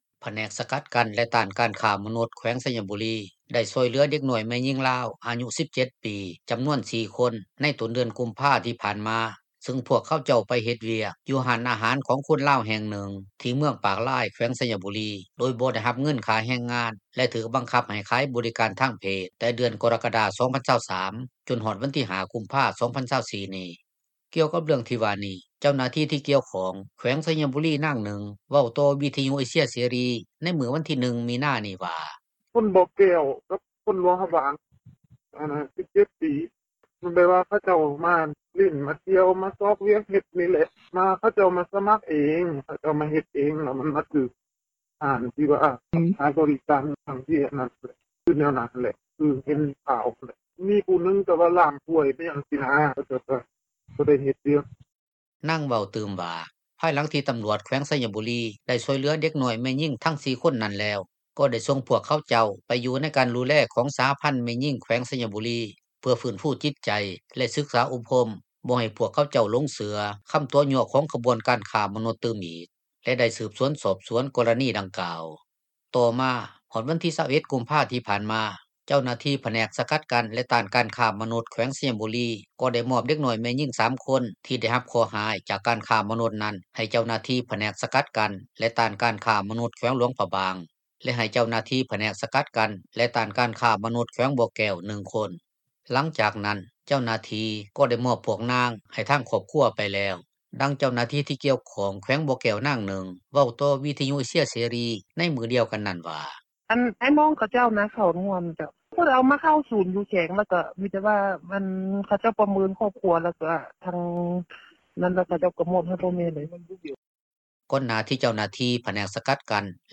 ກ່ຽວກັບເຣື່ອງທີ່ວ່ານີ້ ເຈົ້າໜ້າທີ່ ທີ່ກ່ຽວຂ້ອງ ແຂວງໄຊຍະບູຣີ ນາງນຶ່ງ ເວົ້າຕໍ່ວິທຍຸເອເຊັຍເສຣີ ໃນມື້ວັນທີ 1 ມີນານີ້ວ່າ:
ດັ່ງຄະນະກໍາມະການດັ່ງກ່າວ ທ່ານນຶ່ງເວົ້າວ່າ: